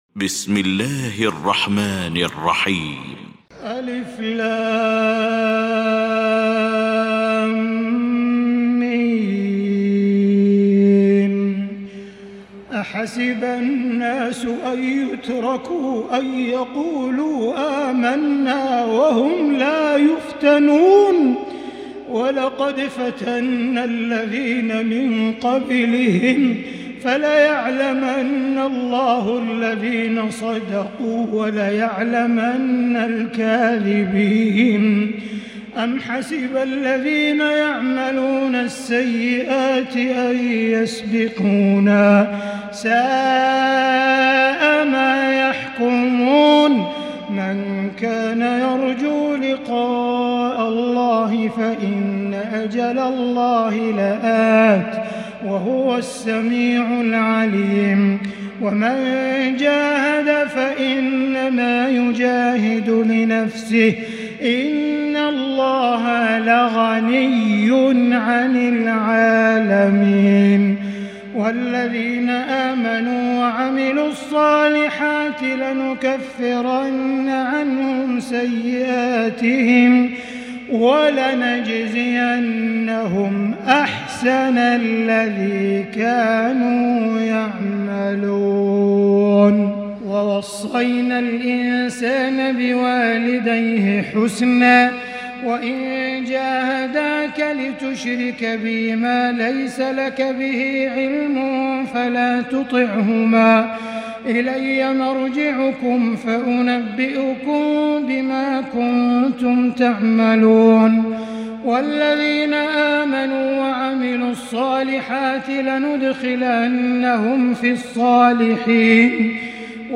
المكان: المسجد الحرام الشيخ: معالي الشيخ أ.د. بندر بليلة معالي الشيخ أ.د. بندر بليلة معالي الشيخ أ.د. عبدالرحمن بن عبدالعزيز السديس العنكبوت